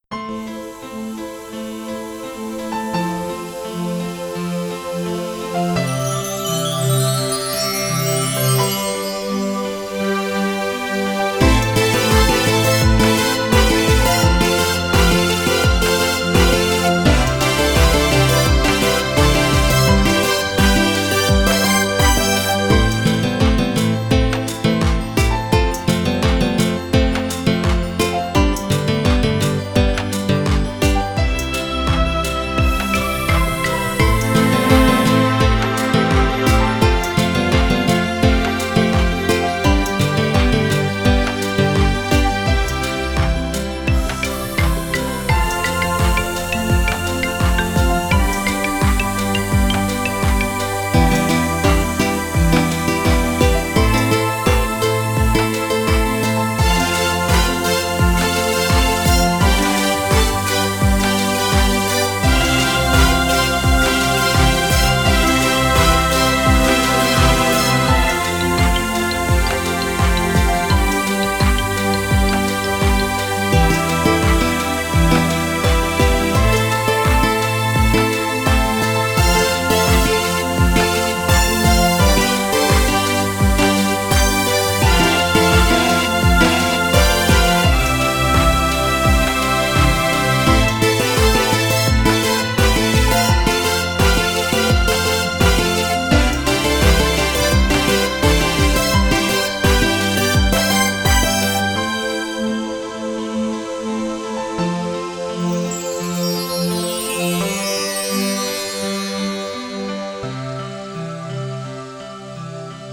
本番はこの曲にボーカルが載ります ピアノ、ストリングス、アコギとマリンバがちょっとしたアクセントに。